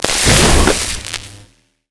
stun crystal.ogg